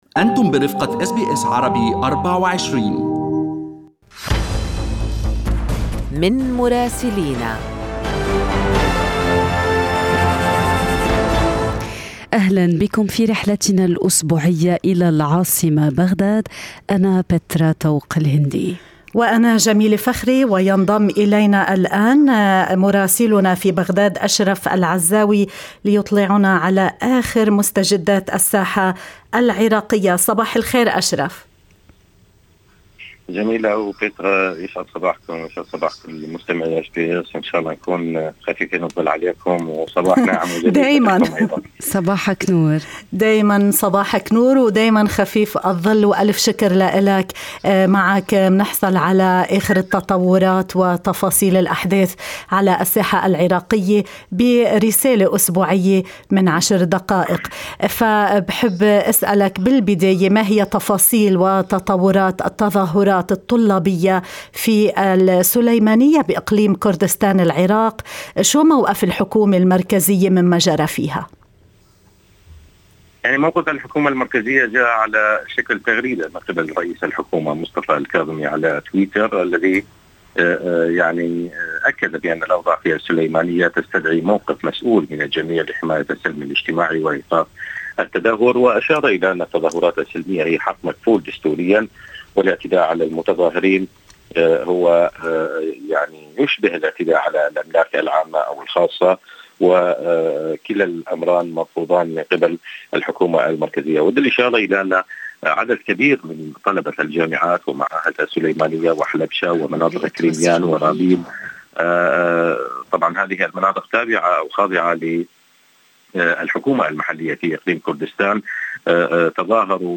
من مراسلينا: أخبار العراق في أسبوع 26/11/2021